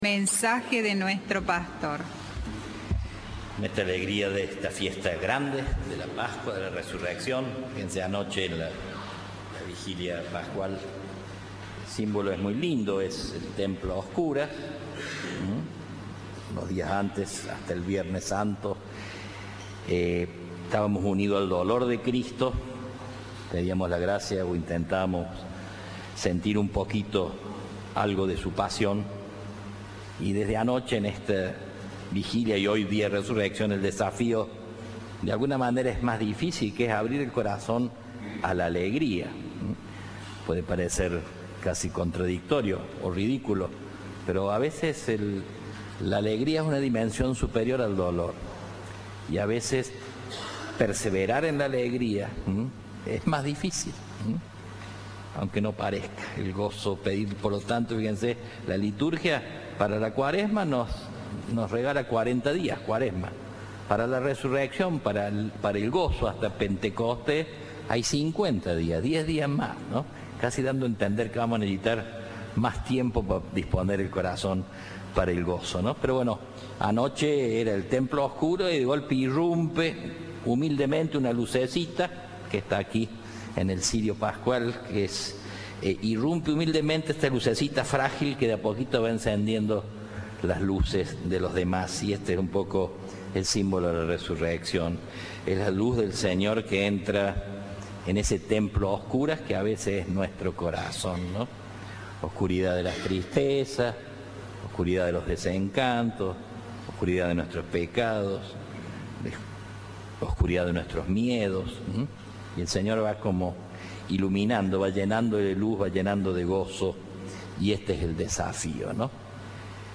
El arzobispo de Córdoba habló del desafío de la alegría en las misa del domingo de Pascua.